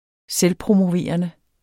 Udtale [ -pʁomoˌveˀʌnə ]